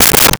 Dresser Drawer Handle 01
Dresser Drawer Handle 01.wav